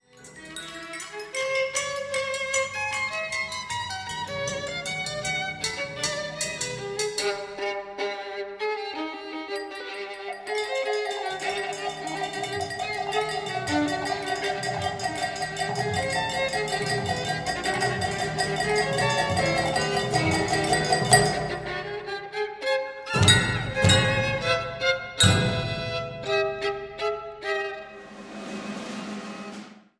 Violine